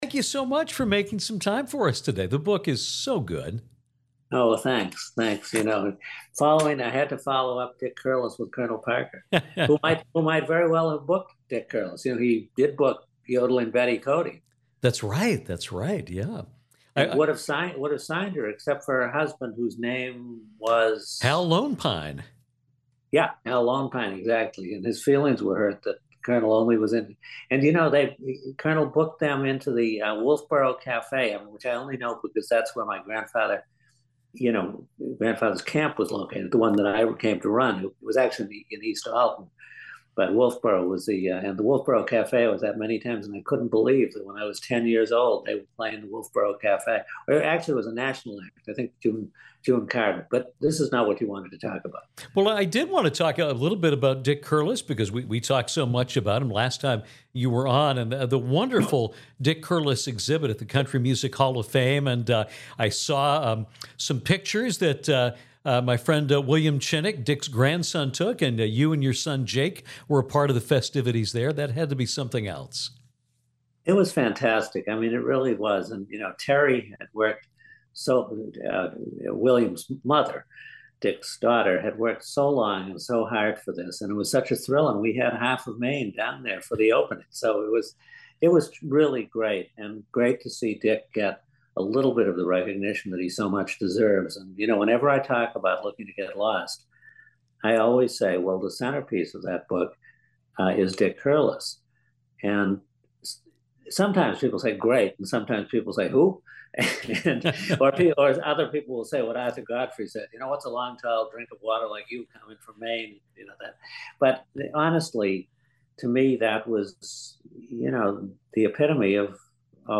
Music journalist and author Peter Guralnick returned to Downtown to talk about his latest book, THE COLONEL AND THE KING: TOM PARKER, ELVIS PRESLEY, AND THE PARTNERSHIP THAT ROCKED THE WORLD. https